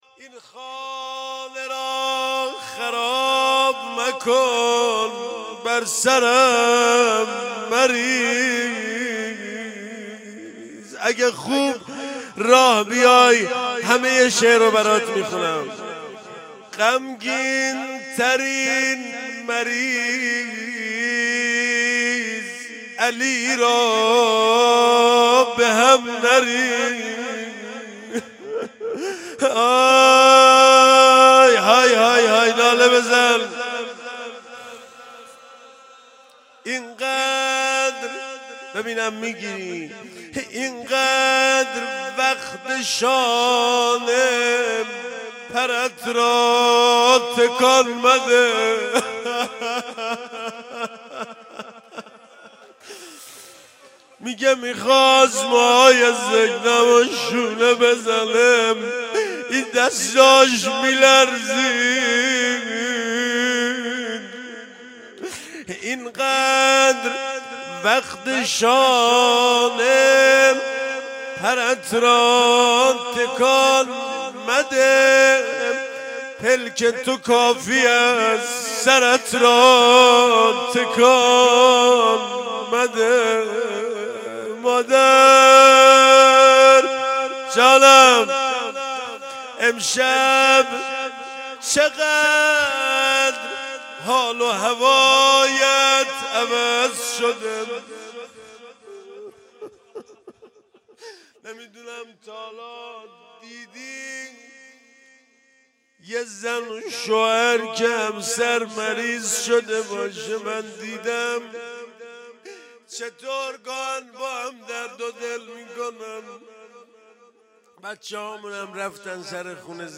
✅روضه شب دوم فاطمیه دوم - این خانه را خراب مکن